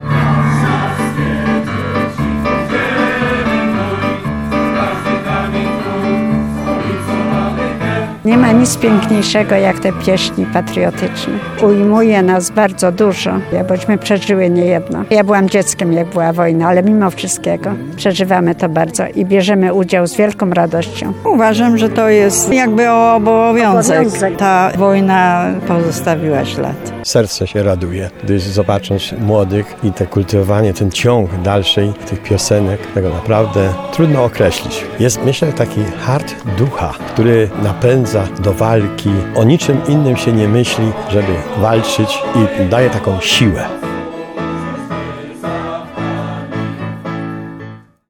Mimo kapryśnej pogody, frekwencja w Parku Strzeleckim dopisała. Jak mówili uczestnicy, pieśni powstańcze są niezwykłe, ponieważ powstawały w trudnych czasach wojny.
Mieszkańcy Tarnowa i regionu, w rocznicę tych wydarzeń, upamiętnili Powstańców, śpiewając wspólnie żołnierskie i powstańcze pieśni.